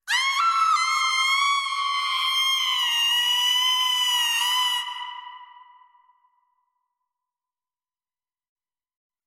Звуки женского крика
Женский крик при падении с высоты